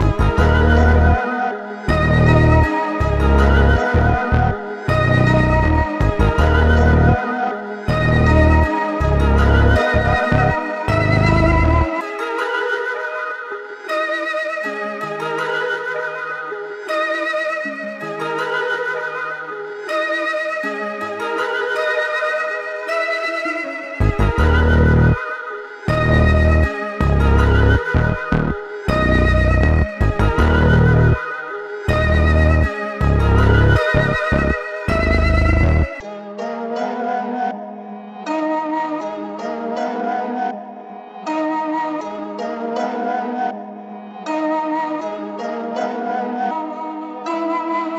La Flame In Japan 160bpm